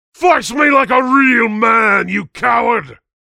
Licensing This is an audio clip from the game Team Fortress 2 .
Saxton_Hale_stabbed_01(1).mp3